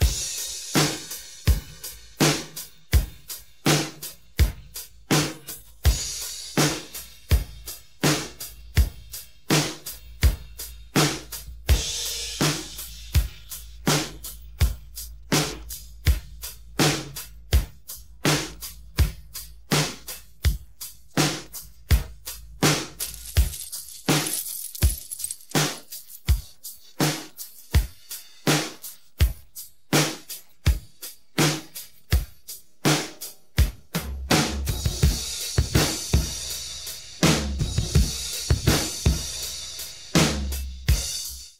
8tel-Beat